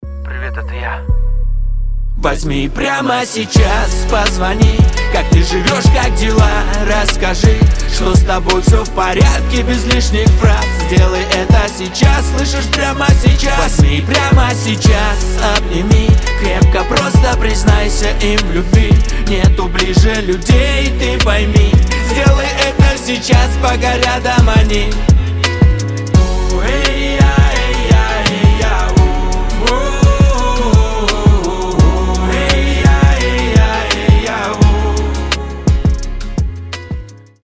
• Качество: 320, Stereo
душевные